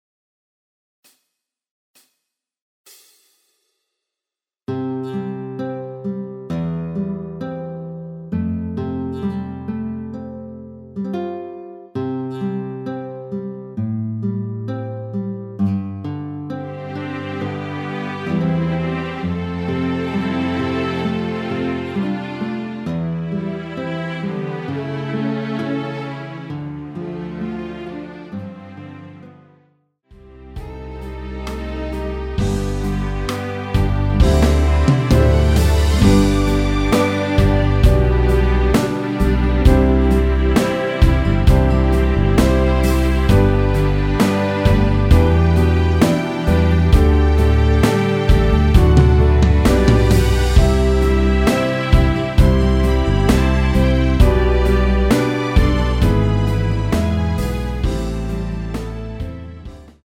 내린 MR
전주없이 시작 하는곡이라 시작 카운트 넣었습니다.(미리듣기 참조)
앞부분30초, 뒷부분30초씩 편집해서 올려 드리고 있습니다.
중간에 음이 끈어지고 다시 나오는 이유는